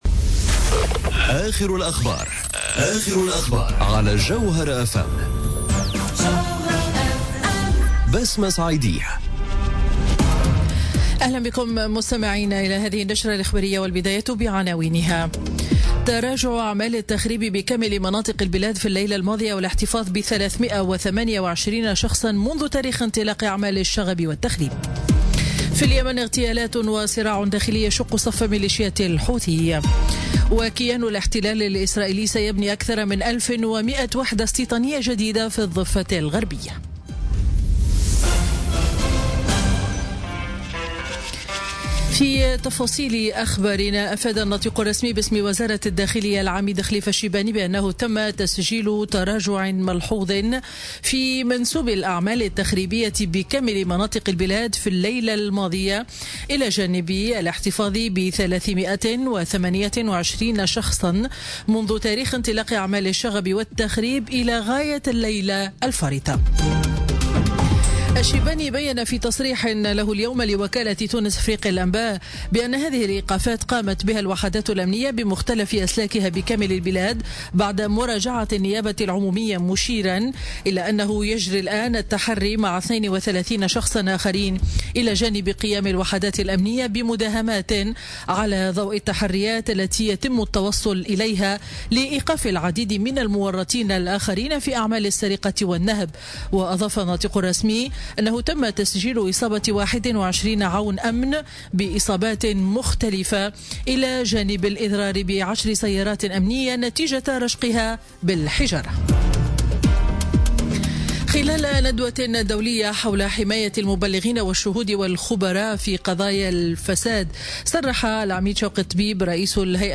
نشرة أخبار منتصف النهار ليوم الخميس 11 جانفي 2017